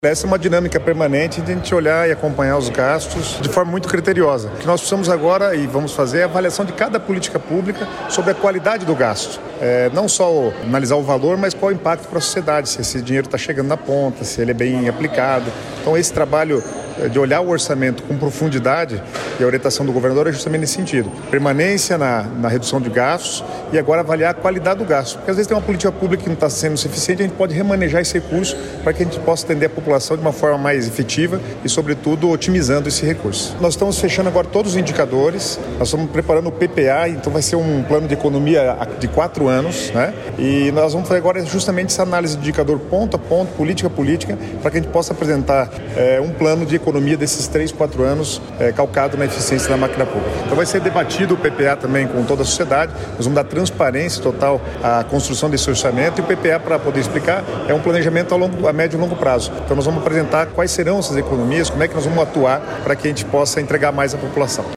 Sonora do secretário do Planejamento, Guto Silva, sobre a nova dinâmica da pasta e a preparação do novo Plano Plurianual do Paraná
GUTO SILVA - REUNIAO SECRETARIADO.mp3